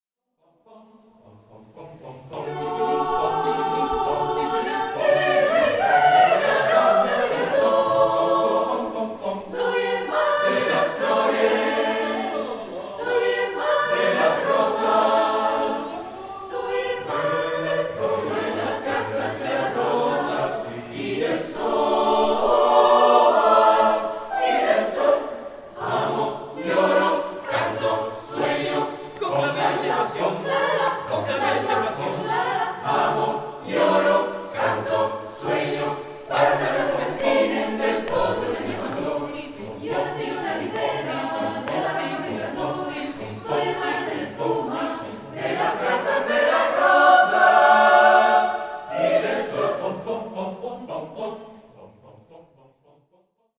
Alma Llanera � una canzone in stile di joropo , che in Venezuela � considerata quasi un inno nazionale.